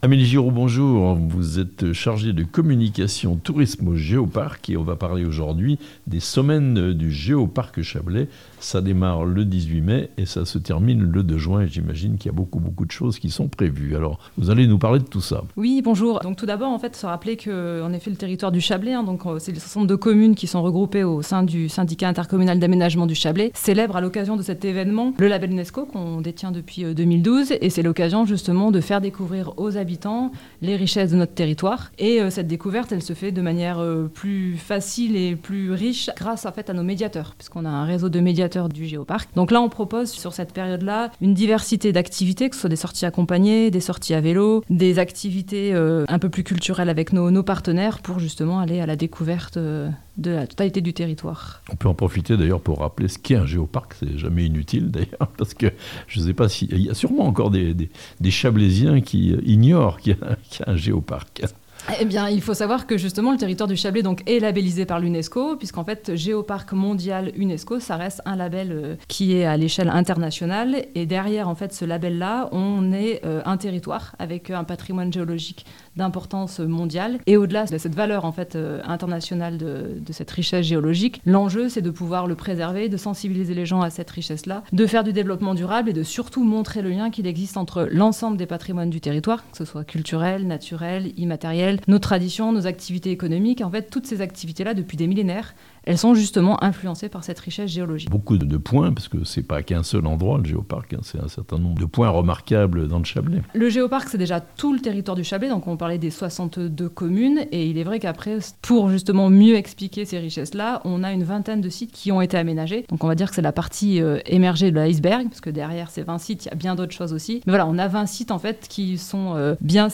Les semaines du Géoparc Chablais, voyage en terre (presque) connue ! - interview -